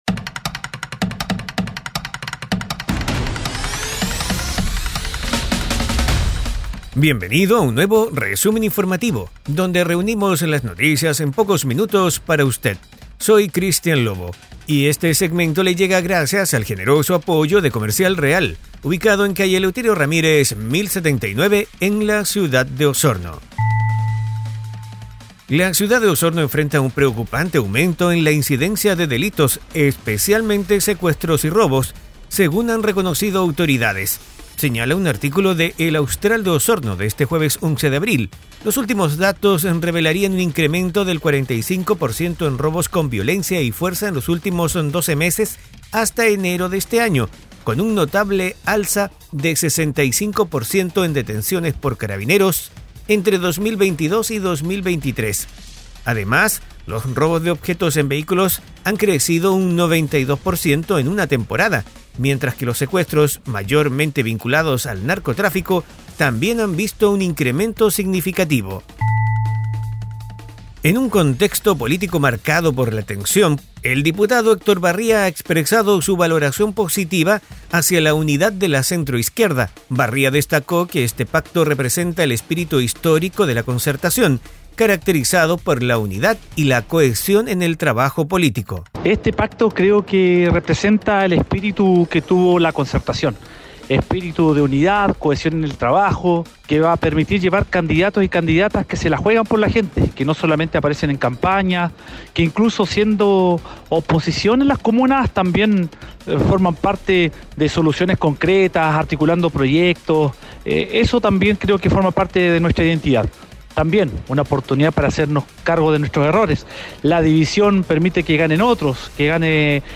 Resumen Informativo 🎙 Podcast 11 de abril 2024